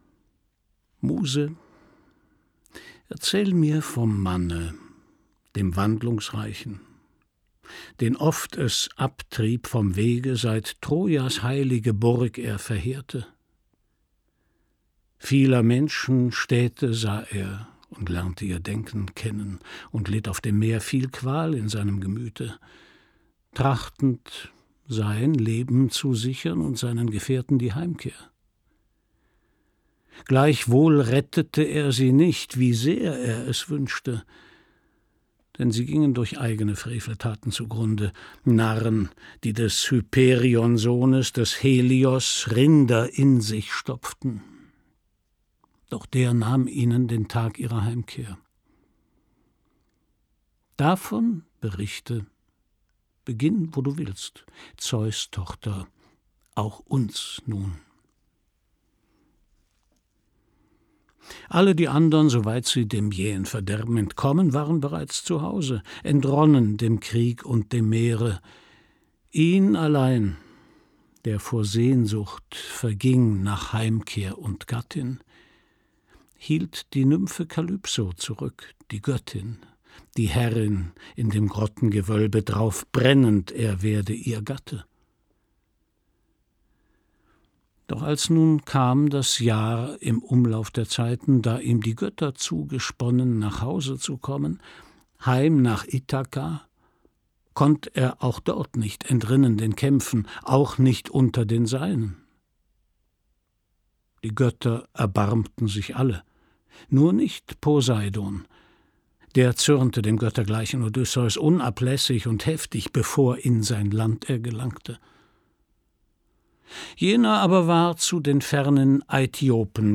Christian Brückner (Sprecher)
2015 | 3. Auflage, Ungekürzte Ausgabe